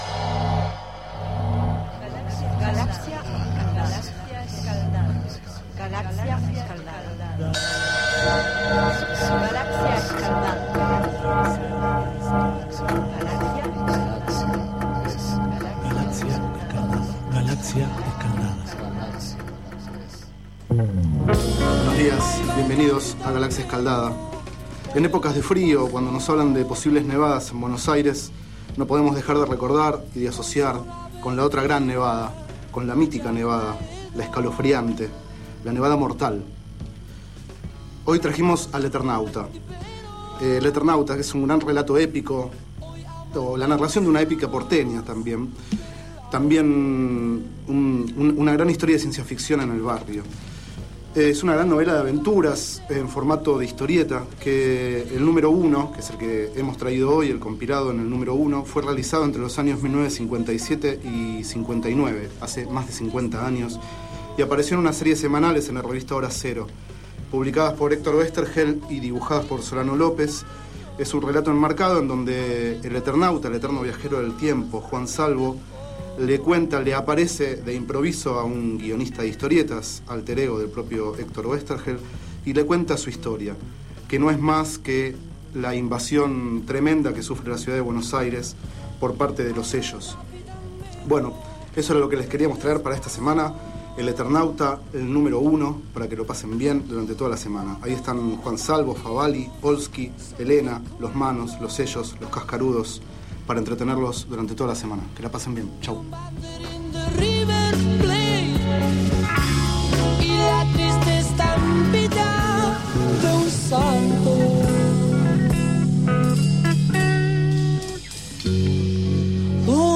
Este es el 18º micro radial, emitido en los programa Enredados, de la Red de Cultura de Boedo, y En ayunas, el mañanero de Boedo, por FMBoedo, realizado el 9 de julio de 2011, sobre el libro El Eternauta, de Héctor G. Oesterheld y Francisco Solano López.
Durante el micro escuchamos el tema El anillo del Capitán Beto, de Invisible (El jardín de los presentes, 1976).